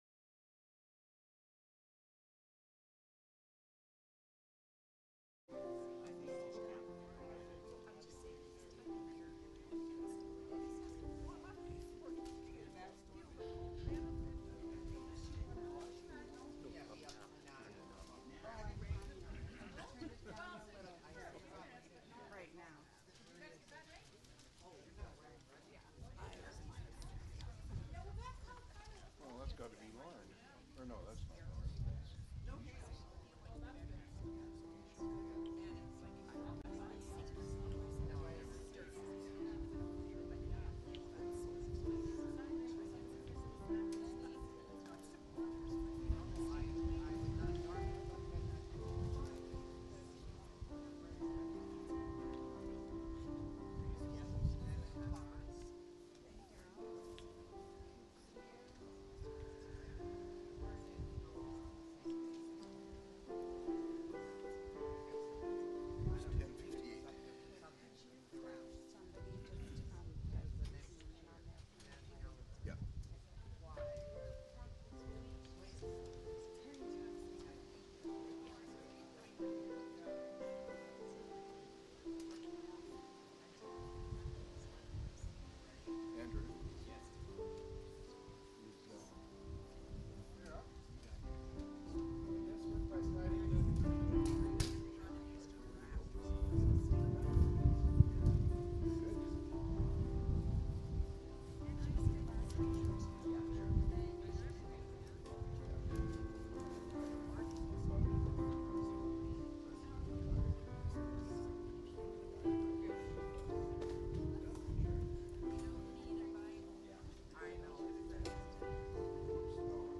1 Kings 19 9-18 Service Type: Sunday Service Bible Text